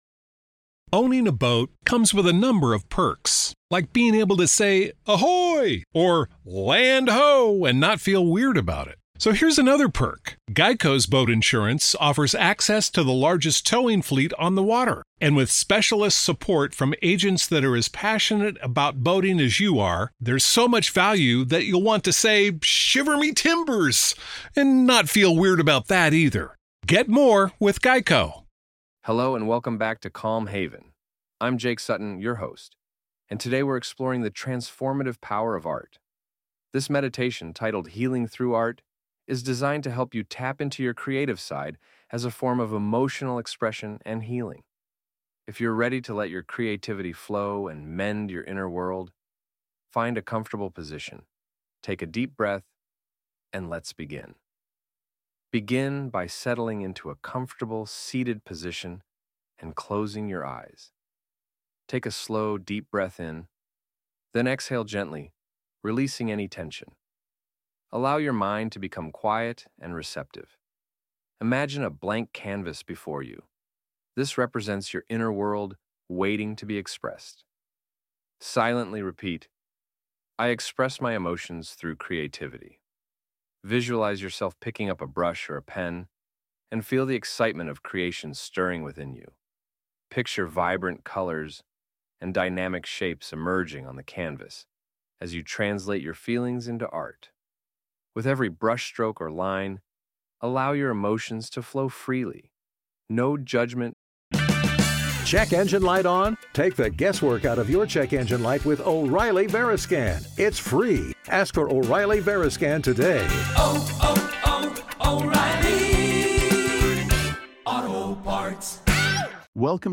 Healing Through Art: A Meditation for Creative Expression